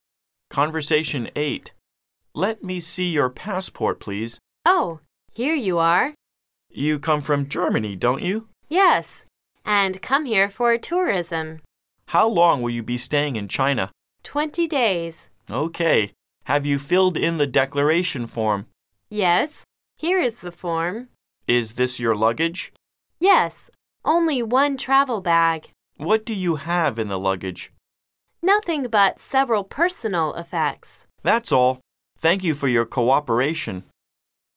Conversation 8